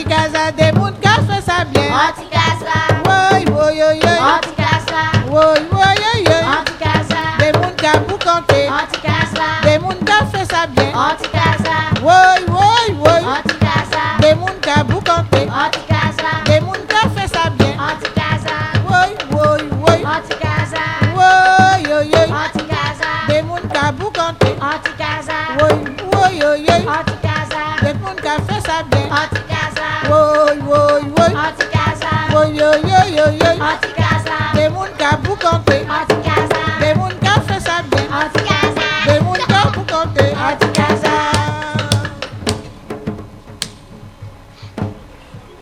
danse : débò (créole)
Pièce musicale inédite